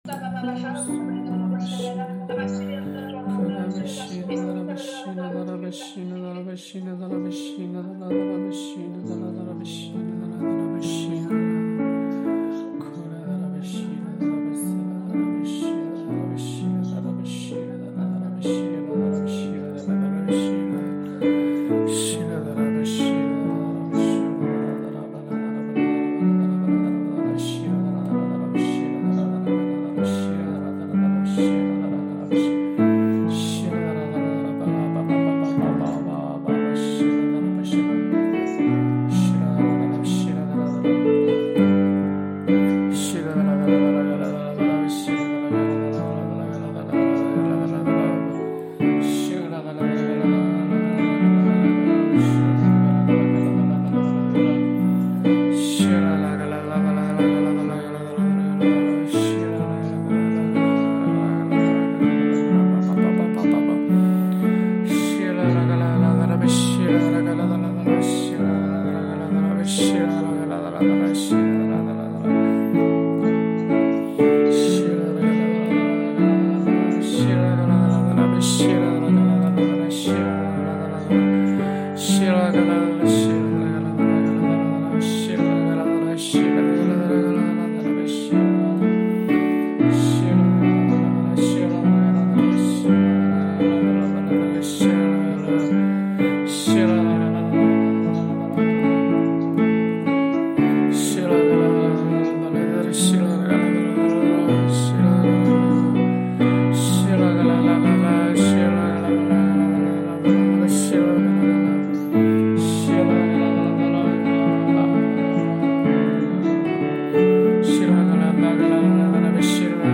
敬拜音乐： 烈火勇士 默想经文及宣告： 当进入第四步，也用 1189 经文默想的方式，来选择 被圣灵感动要默想或者宣告的经文，来为五执华人国 际团队，来为家庭，来为自己进行经文的场景中默想，祷告，宣告，突破！ HAKA祷告敬拜MP3 启示性祷告： 持续祷告：祈求神的旨意成就在我们的身上，带领做新事！